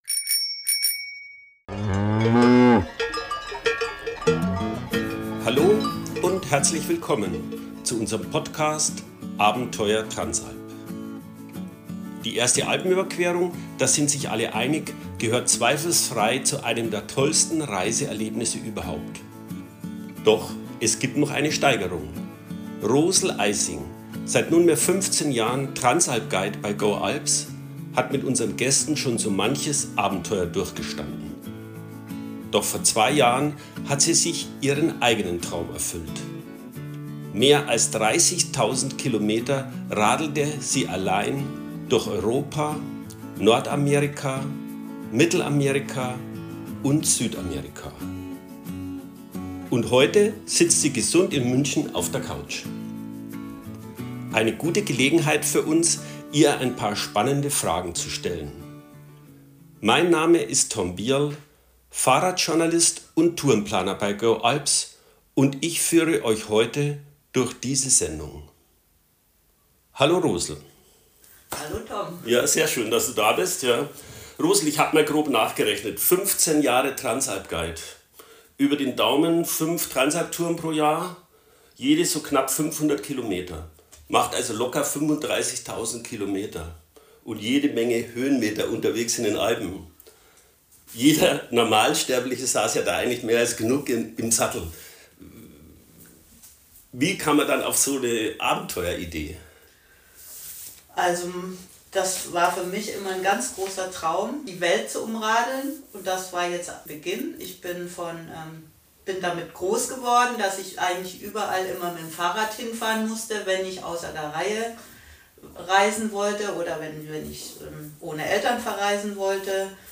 In diesem Podcast berichtet sie über ihr Abenteuer.